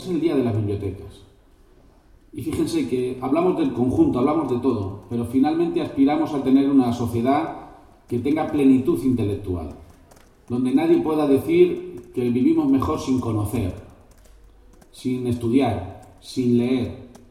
Presidente Sábado, 24 Octubre 2015 - 2:45pm Por otro lado, el jefe del Ejecutivo regional ha aprovechado su intervención para felicitar a todos los bibliotecarios en el Día de las Bibliotecas.
audio_presidente_dia_biblioteca.mp3